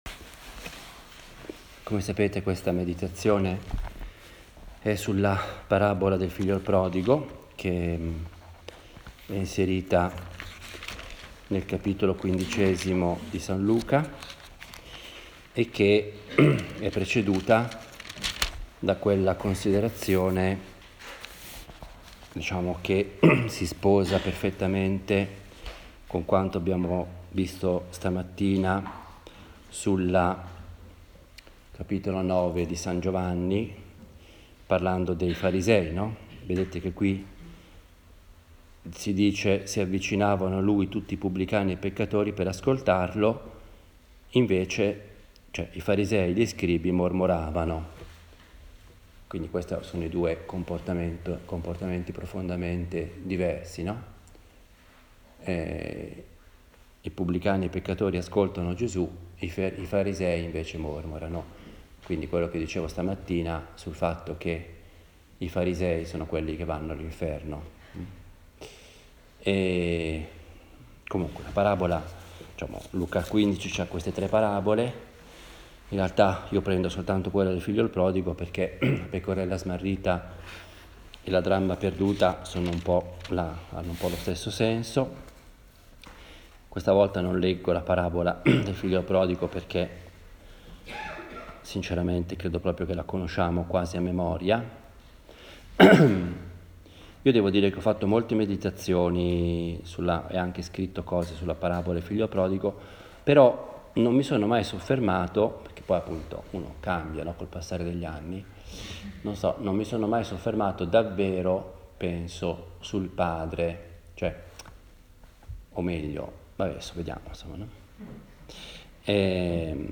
Ho registrato questa meditazione durante gli esercizi spirituali che ho predicato dal 9 gennaio
Una “meditazione” è un genere omiletico diverso dalla predica, dal discorso, o dall’allocuzione. Ha il carattere piano, proprio di una conversazione familiare e io la intendo come il mio dialogo personale – fatto ad alta voce – con Dio, la Madonna, ecc. In genere do un titolo alle meditazione e cerco di fare molto riferimento alla scrittura, in particolare al vangelo.